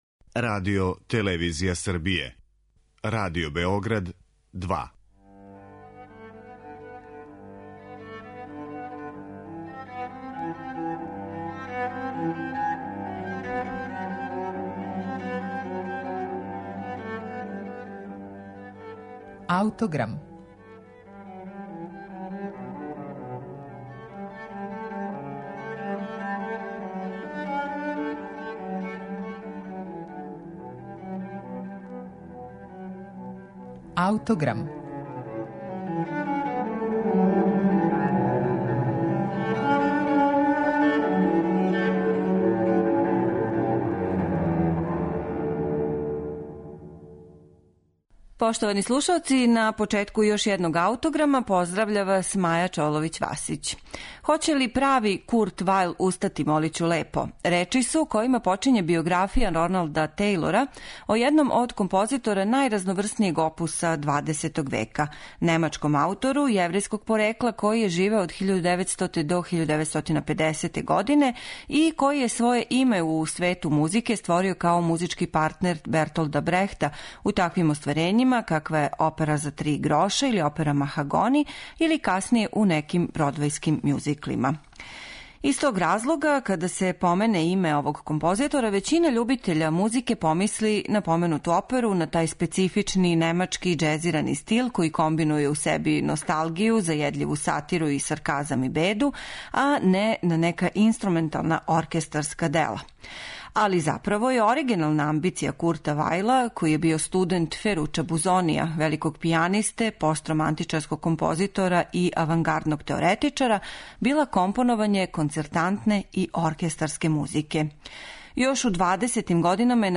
Премијерним извођењем у Амстердаму 1934. године дириговао је Бруно Валтер, а у данашњој емисији слушаћете снимак Гевандхаус оркестра из Лајпцига, којим диригује Едо Де Ваарт.